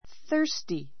thirsty 中 A2 θə́ː r sti さ ～ ス ティ 形容詞 比較級 thirstier θə́ː r stiə r さ ～ ス ティア 最上級 thirstiest θə́ː r stiist さ ～ ス ティエ スト ❶ 喉 のど の渇 かわ いた , 喉が渇いて I am [feel] very thirsty.